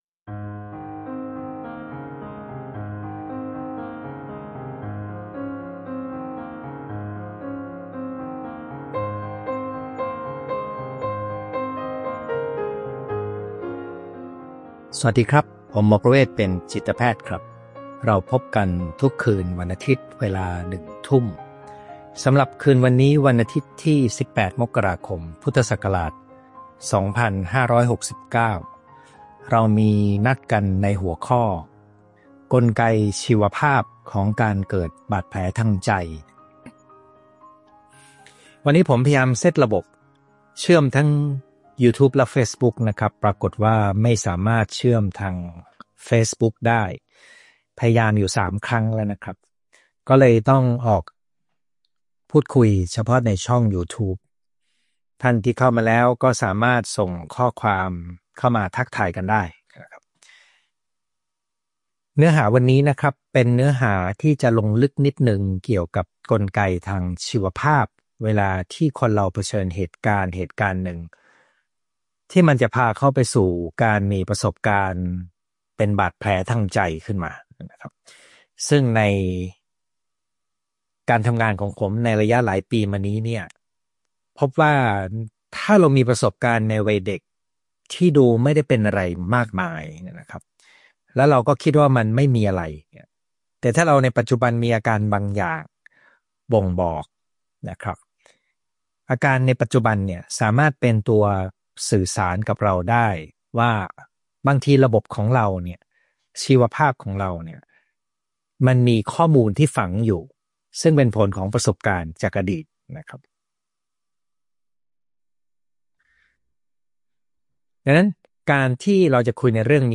ไลฟ์ประจำวันอาทิตย์ที่ 18 มกราคม 2569 เวลาหนึ่งทุ่ม